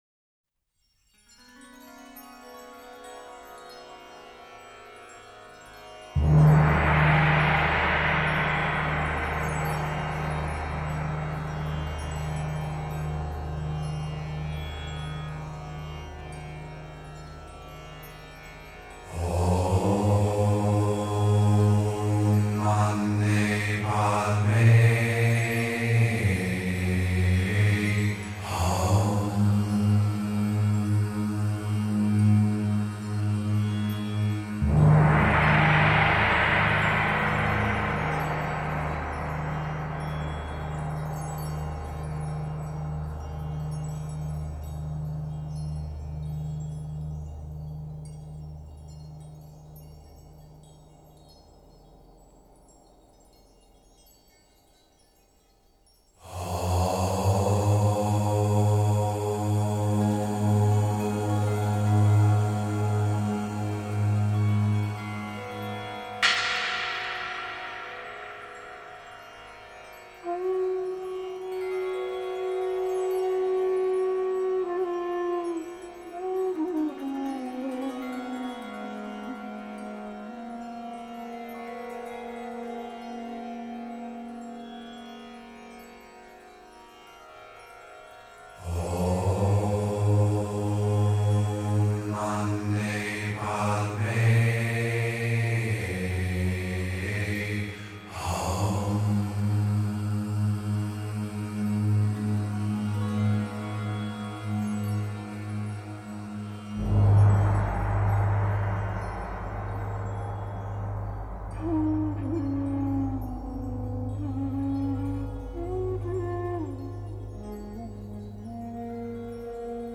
ESCUCHA EL MANTRA AQUI Es bueno recitar el mantra OM MANI PADME HUM, pero mientras lo haces deberías pensar en su sigificado.